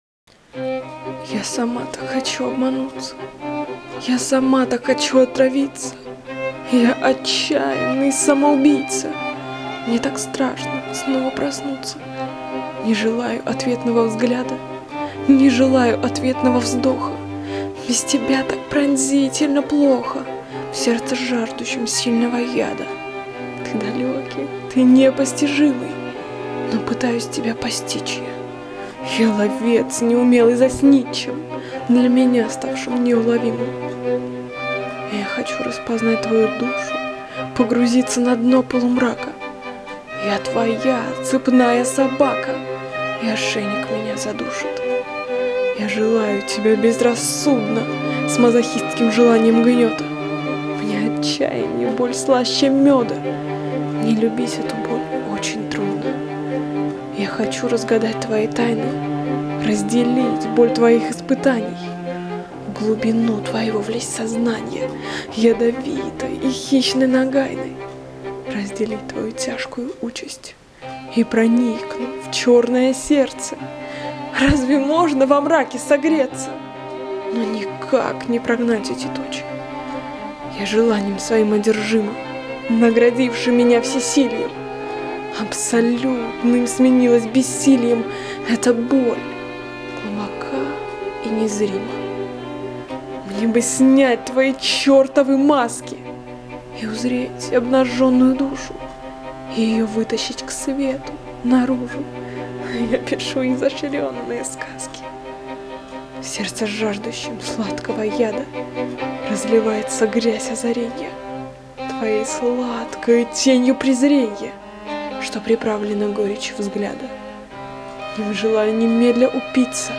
Рубрика: Поезія, Лірика